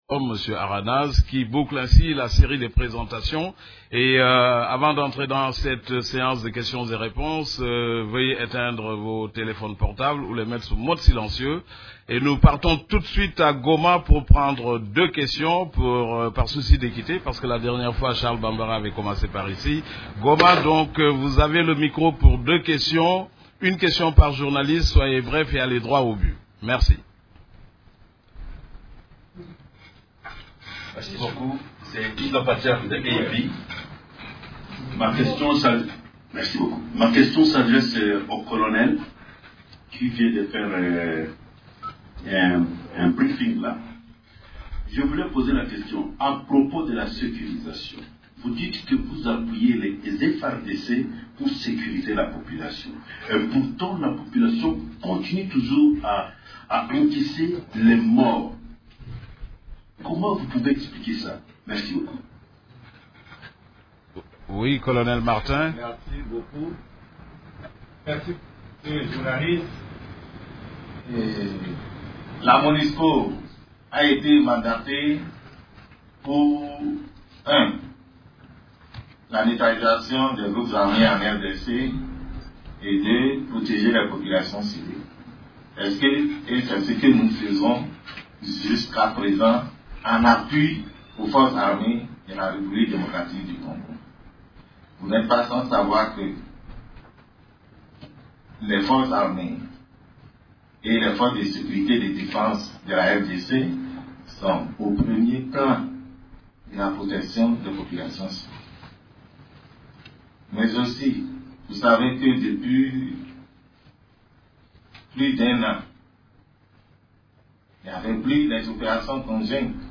Conférence de presse du 13 avril 2016
La conférence de presse hebdomadaire des Nations unies du mercredi 13 avril à Kinshasa a porté sur les activités des composantes de la Monusco, les activités de l’équipe-pays et la situation militaire.